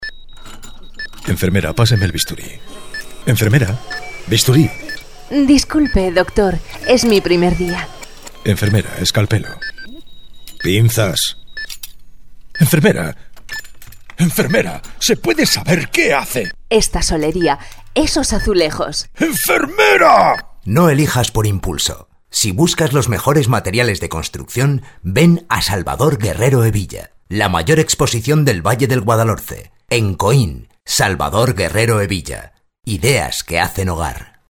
Commercial voice overs   Commercial voice overs
Spot local television.